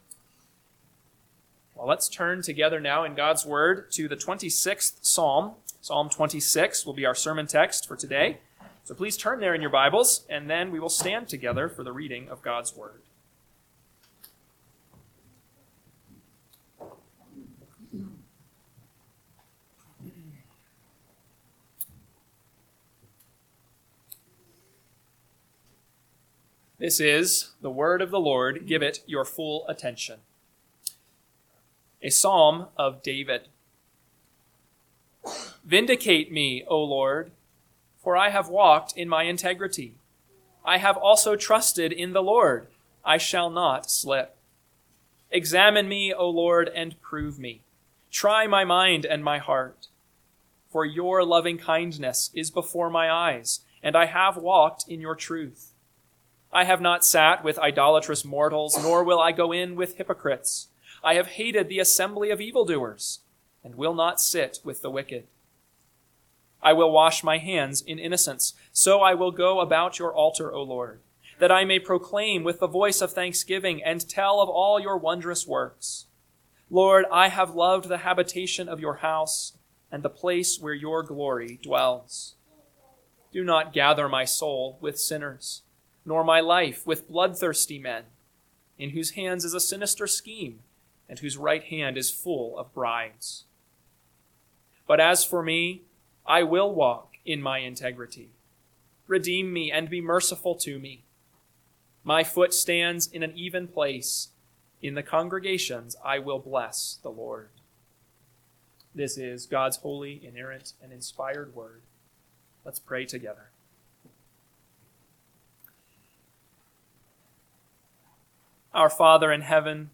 AM Sermon – 7/27/2025 – Psalm 26 – Northwoods Sermons